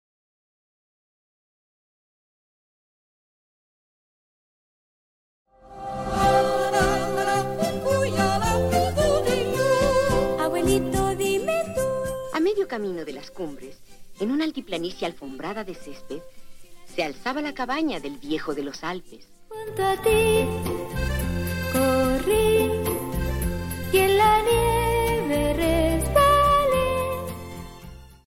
Sonido HiRes 24-bit 192Khz restaurado manualmente.